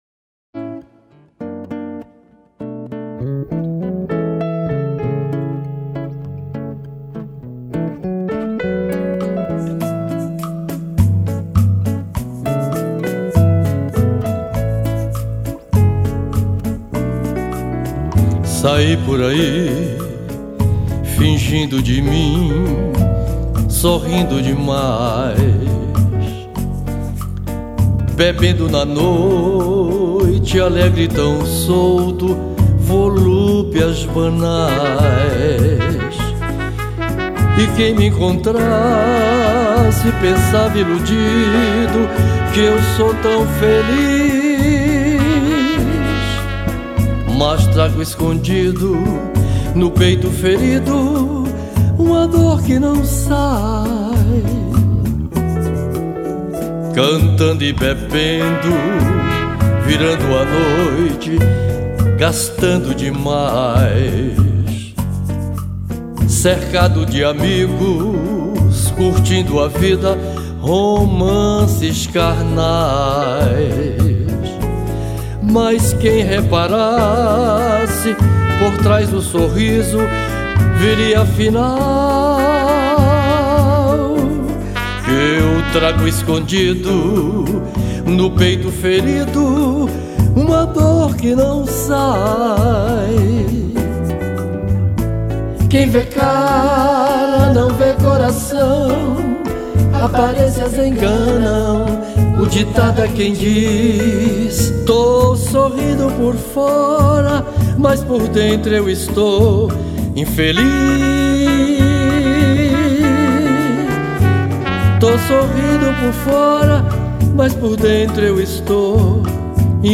2285   04:14:00   Faixa:     Bolero